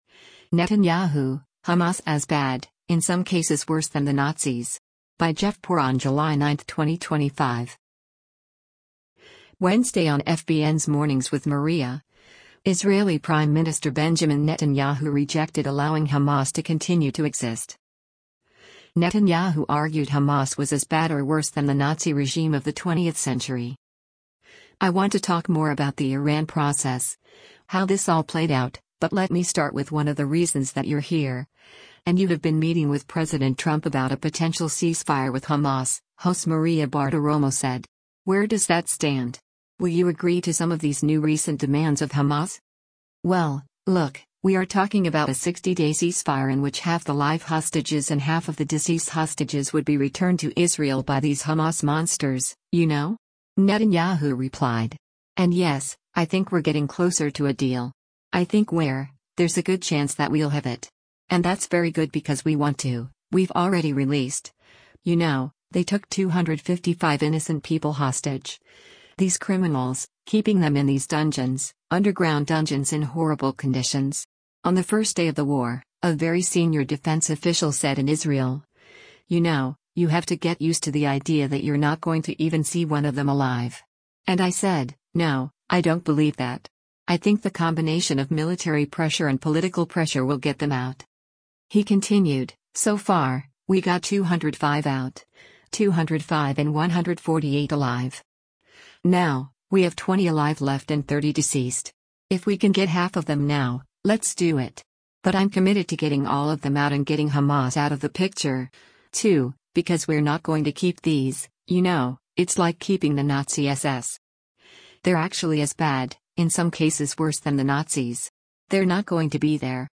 Wednesday on FBN’s “Mornings with Maria,” Israeli Prime Minister Benjamin Netanyahu rejected allowing Hamas to continue to exist.